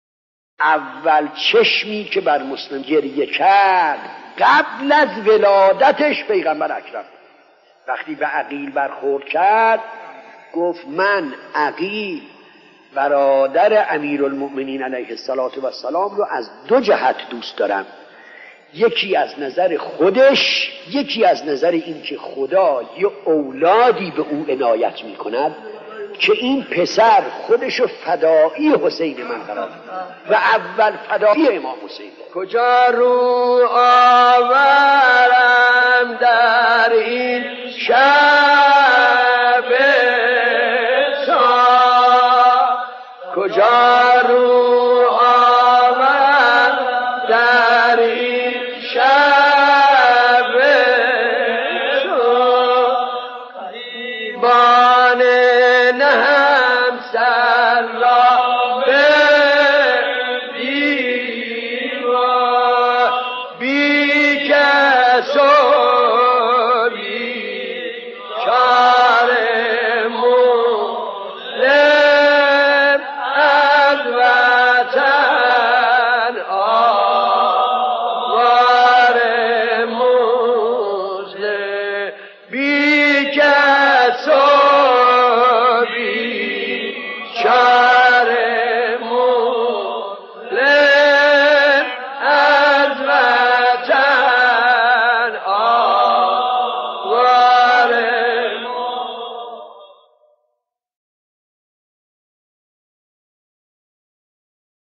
نوحه‌خوانی گروهی از زبان مسلم بن عقیل